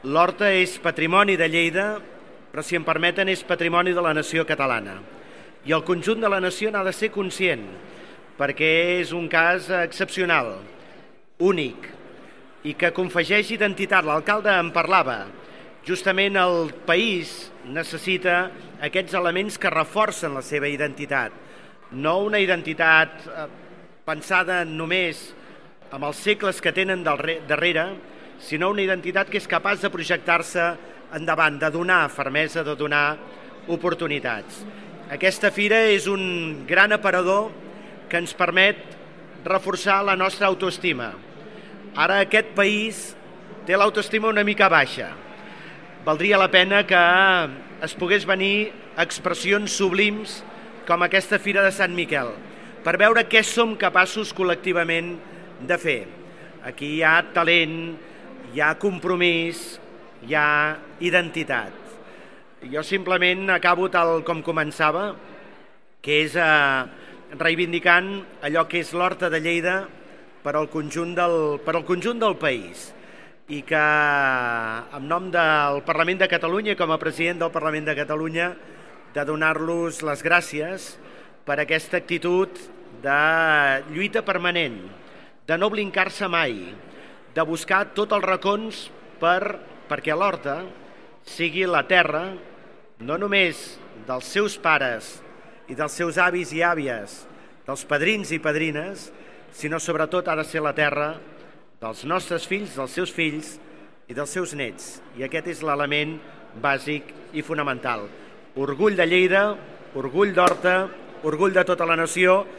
Tall de veu del paer en cap, Fèlix Larrosa, sobre el Dia de l'Horta a la Fira de Sant Miquel de Lleida.